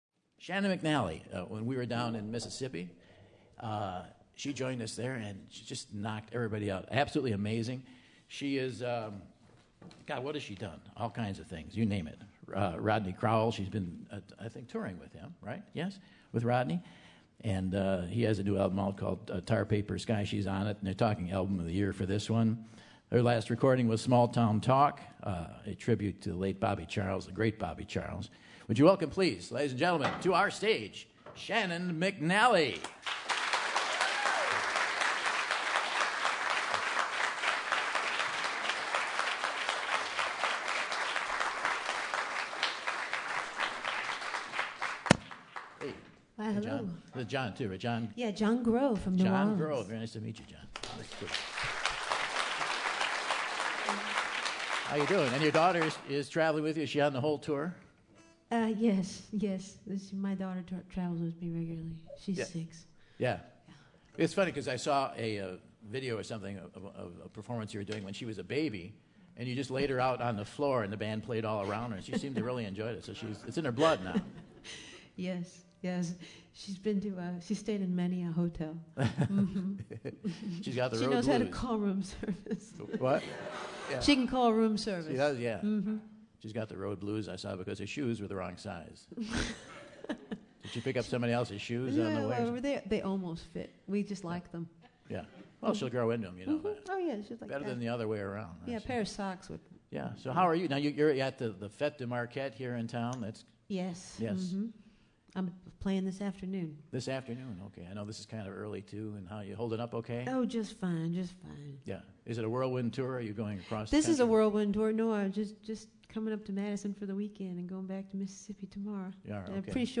southern blues and rock traditions